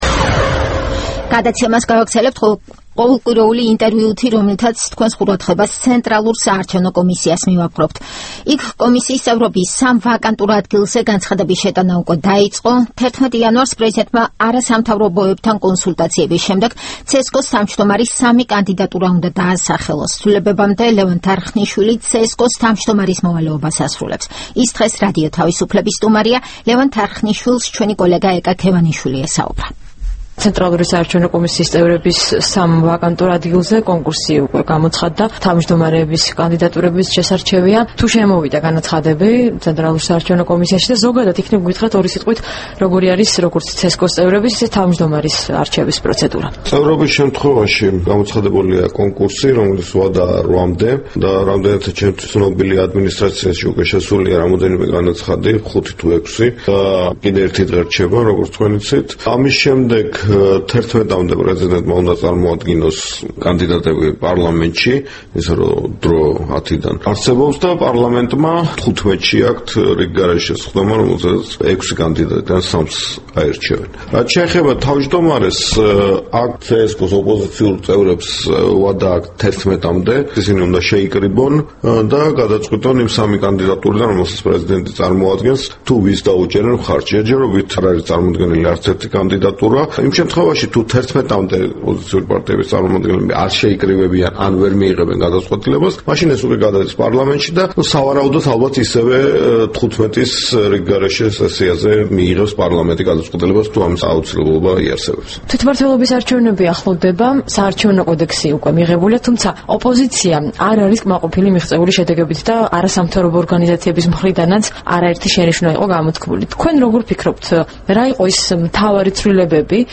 ინტერვიუ ლევან თარხნიშვილთან